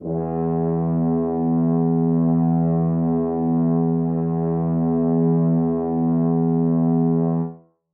brass / horn-section / samples / E2_mp.mp3
E2_mp.mp3